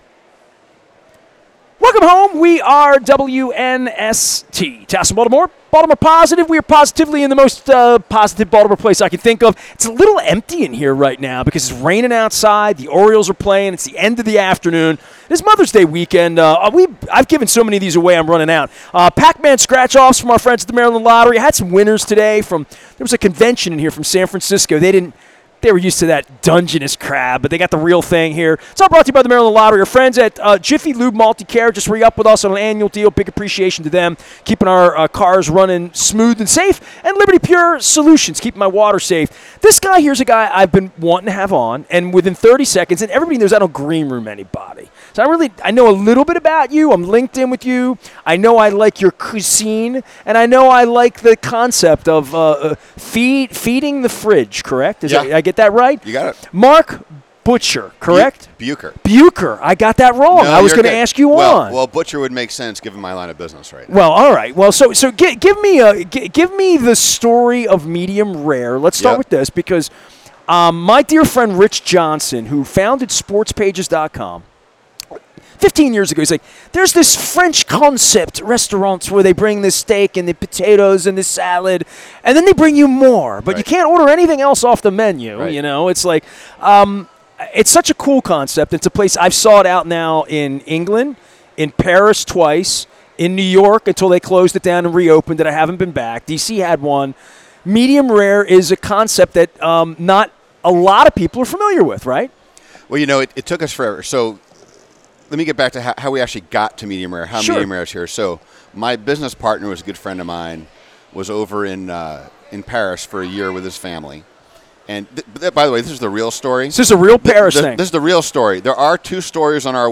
at Faidley's Seafood on the Maryland Crab Cake Tour presented by The Maryland Lottery, Jiffy Lube and Liberty Pure Solutions.